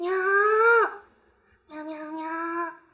miya语音包重录